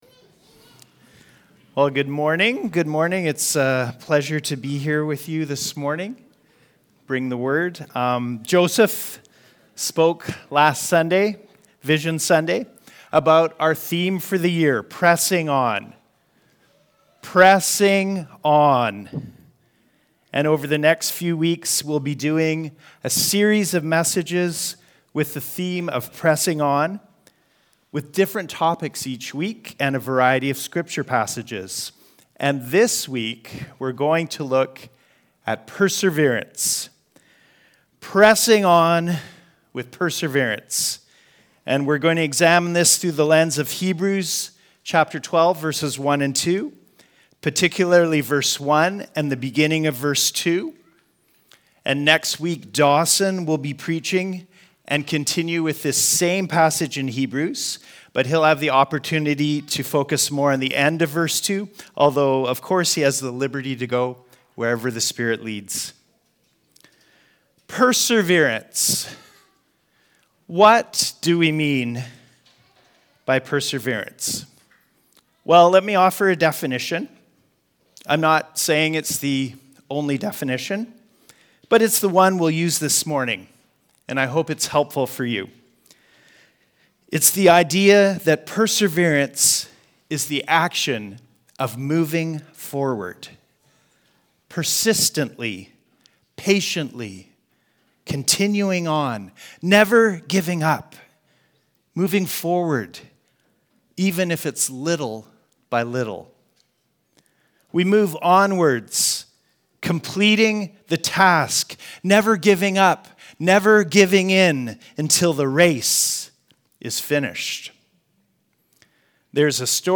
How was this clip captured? Recordings from Oceanside Community Church in Parksville, BC, Canada